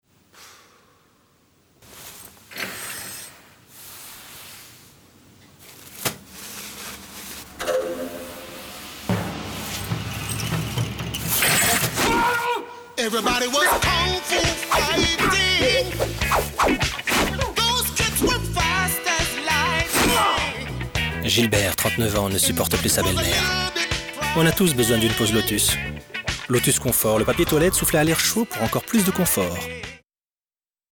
demo voix mp3